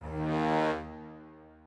strings7_22.ogg